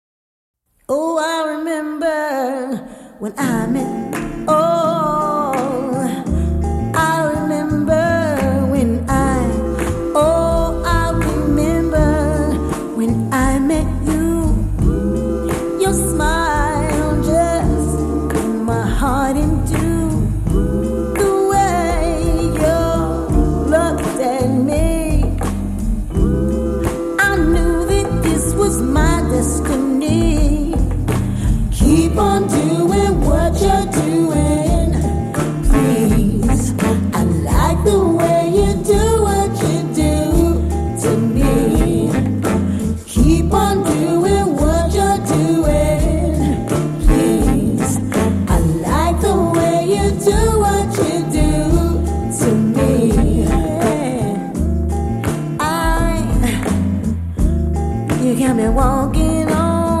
raw funk and soul
Soul